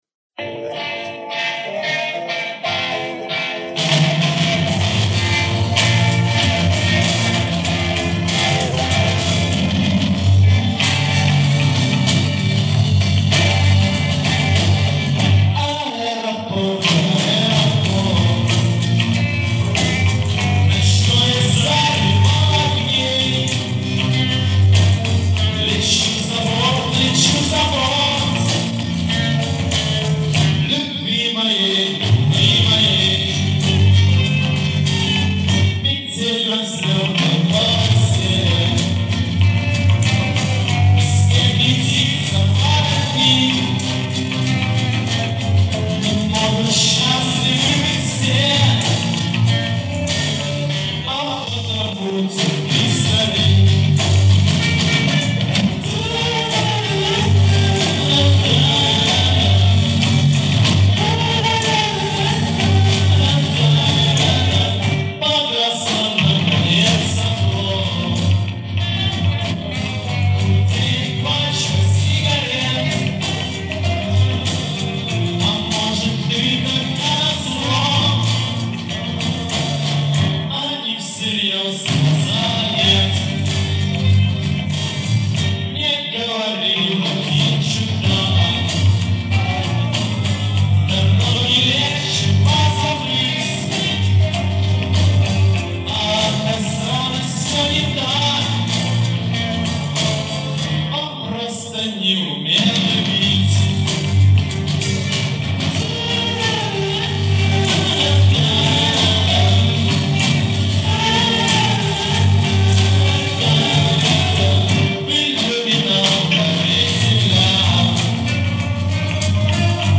Убраны провалы.